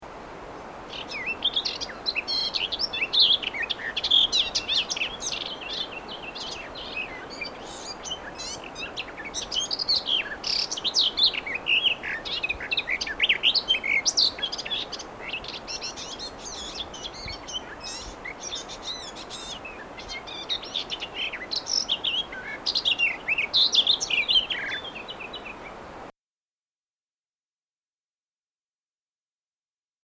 Här nedan hittar du en lista med fågelsång och fågelläten av mer än 400 fågelarter.
Trädgårdssångare Garden Warbler Sylvia borin
Tradgardssangare.mp3